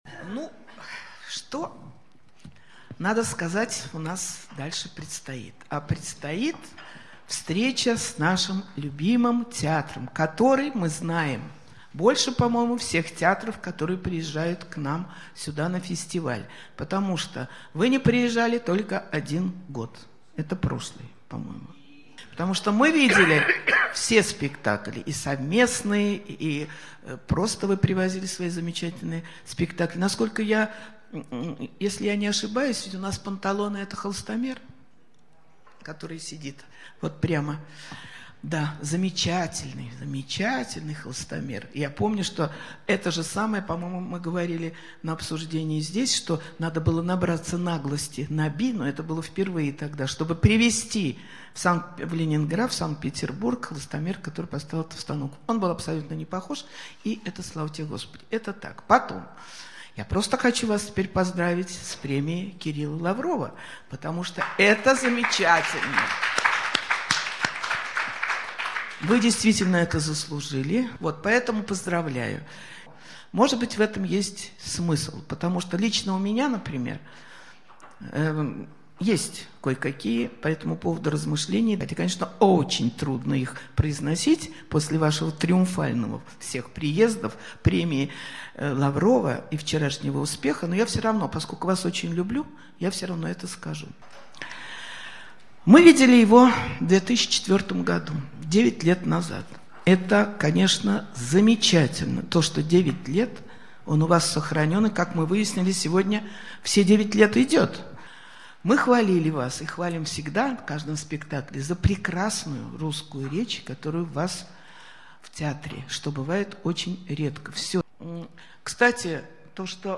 Аудиозапись. Мнения критиков. "Встречи в России" в Санкт-Петербурге, 2013 г.
turandot_discussion.mp3